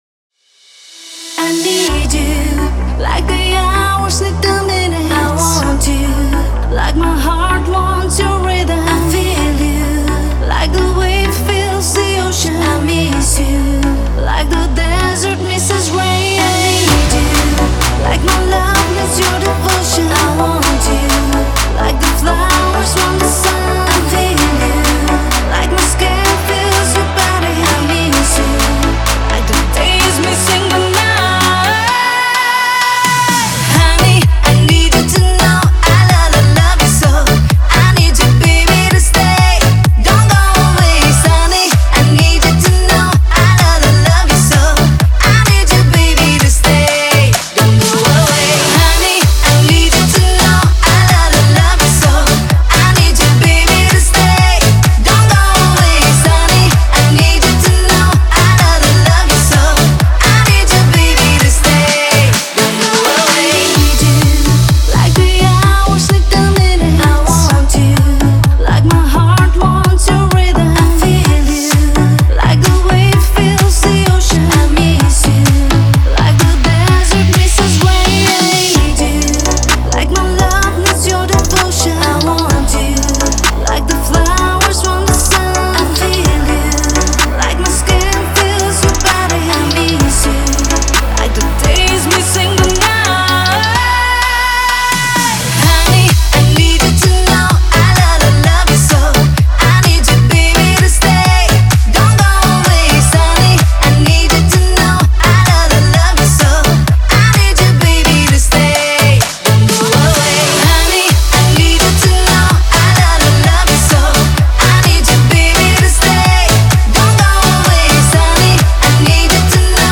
это зажигательная танцевальная композиция в жанре EDM